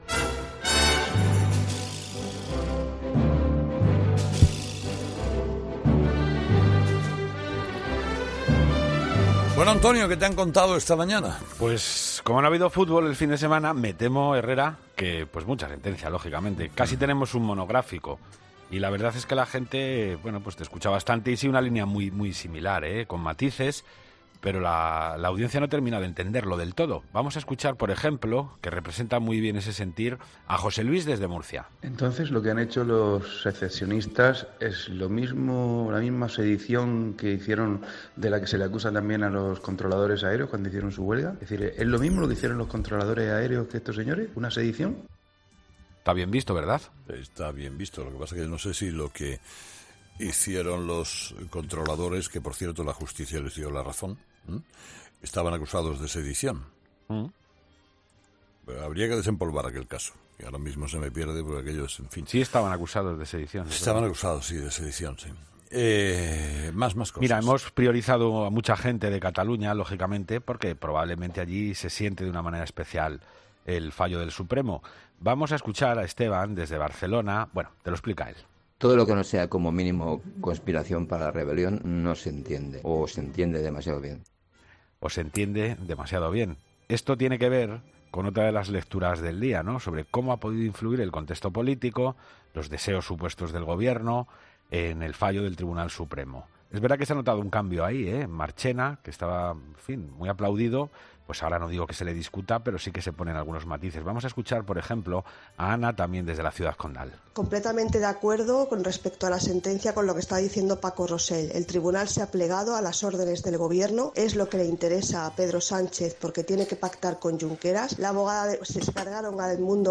Los oyentes, de nuevo, protagonistas en 'Herrera en Cope' con su particular tertulia.